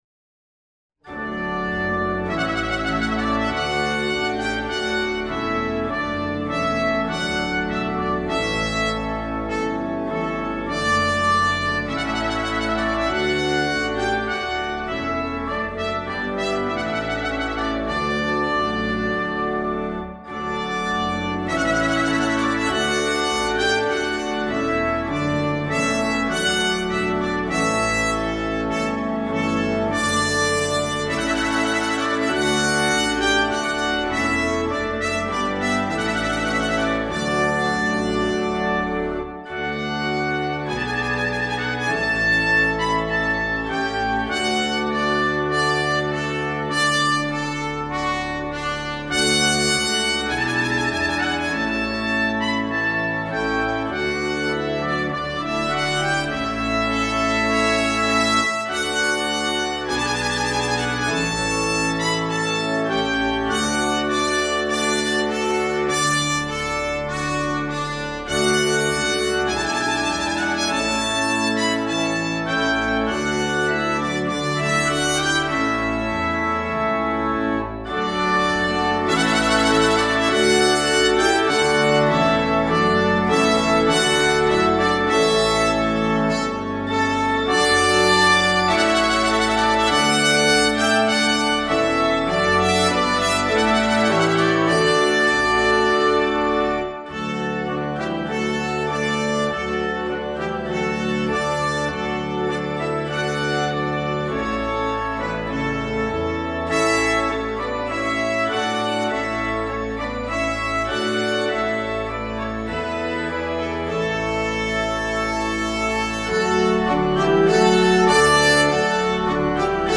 Duett
mit Orgel
Klassik
Orgelbegleitung